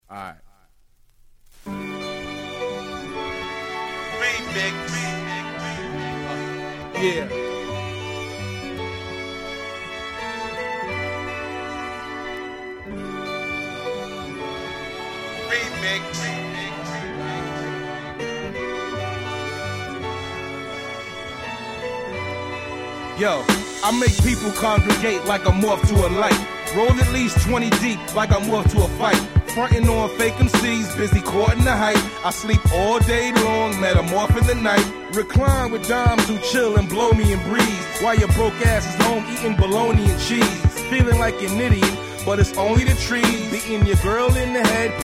サビの「ナーナナーナナ」の部分は大合唱必死、メロウなトラックが素晴らし過ぎて死ねます。
ダイアモンド 90's Boom Bap ブーンバップ プロモオンリー